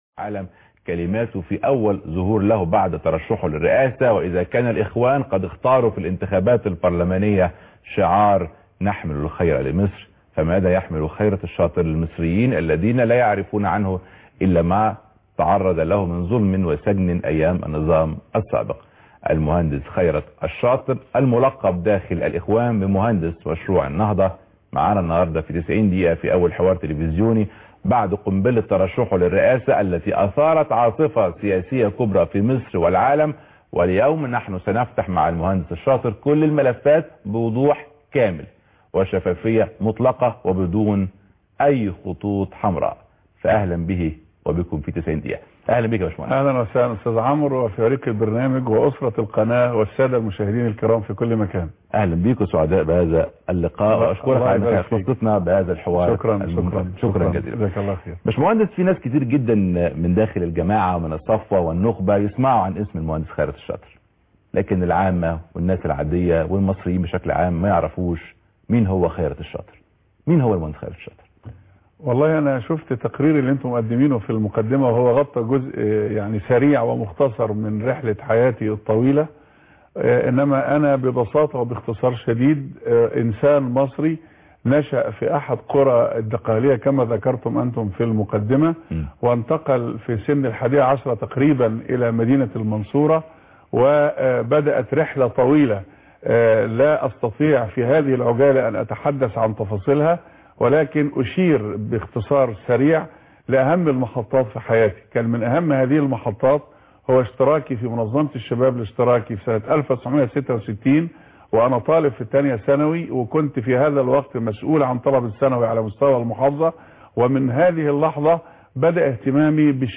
لقاء مع خيرت الشاطر فى قناة المحور ( 9/4/2012 ) - قسم المنوعات